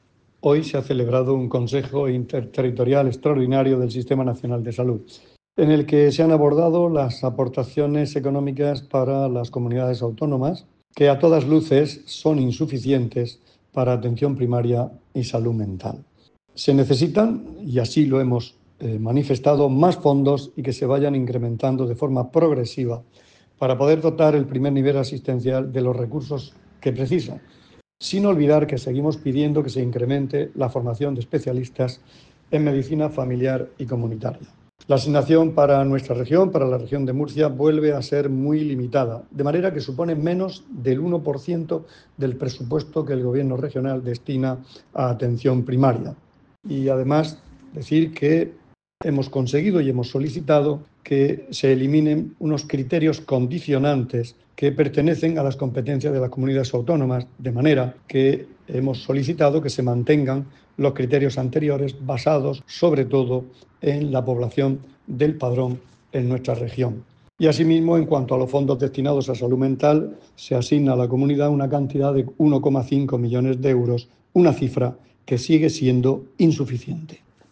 Declaraciones del consejero de Salud, Juan José Pedreño, tras el pleno extraordinario del Consejo Interterritorial del Sistema Nacional de Salud.